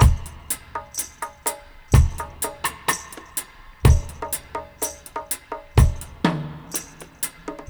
62-FX+PERC2.wav